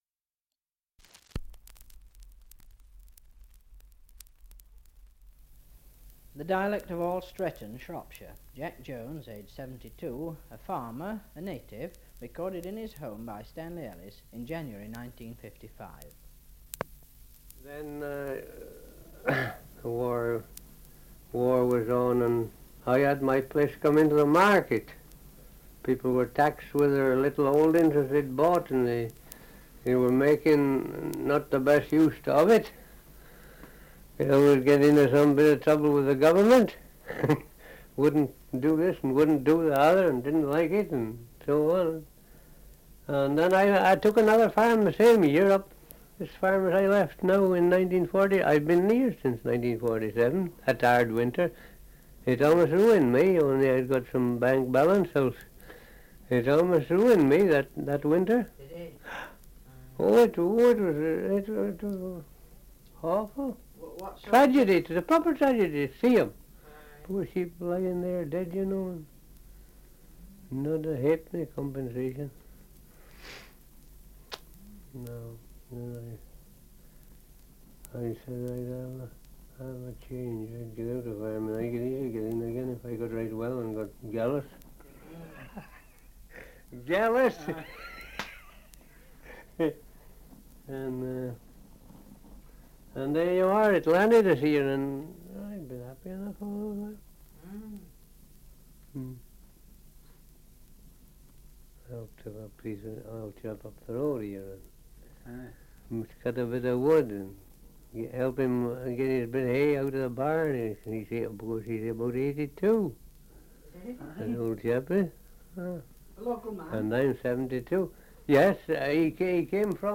Survey of English Dialects recording in All Stretton, Shropshire
78 r.p.m., cellulose nitrate on aluminium